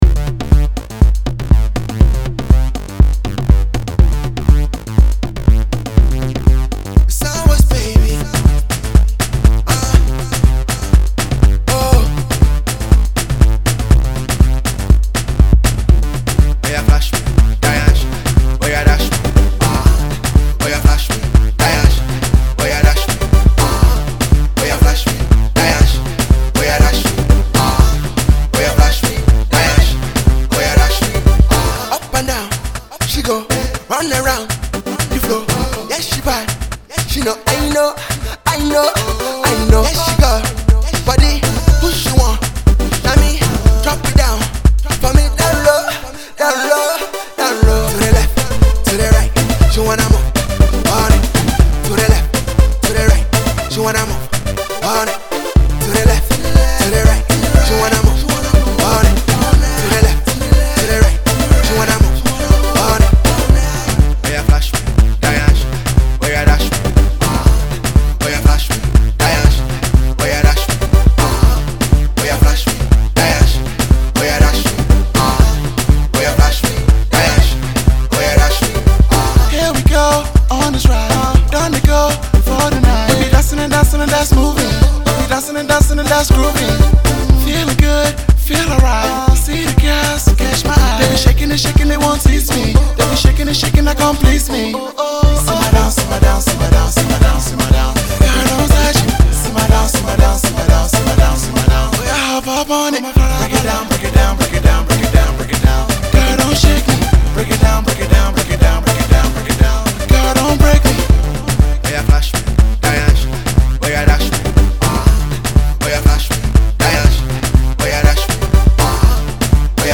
entertainingly funny and original jam